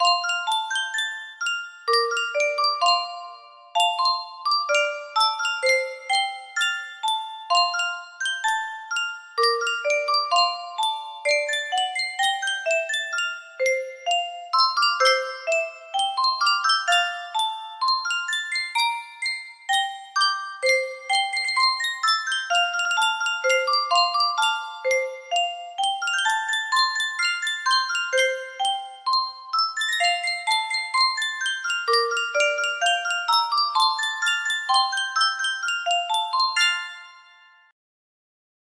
Japanese Picnic Children's Song music box melody
Full range 60